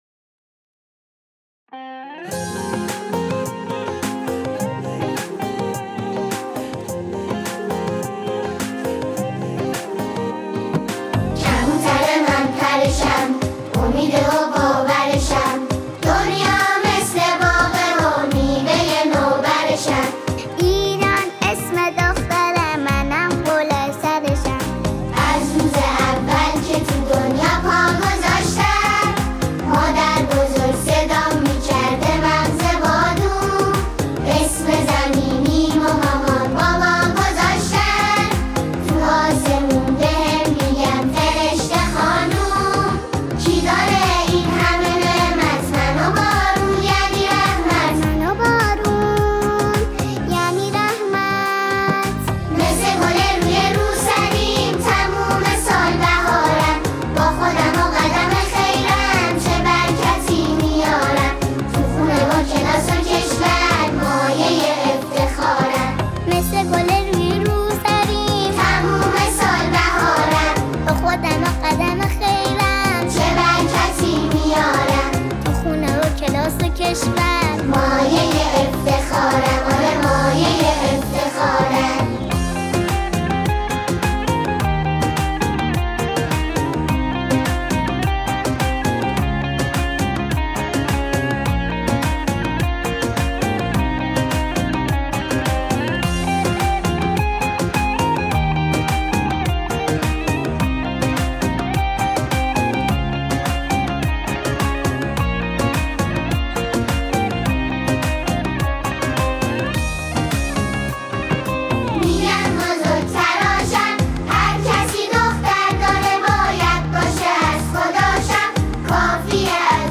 ژانر: سرود ، سرود مناسبتی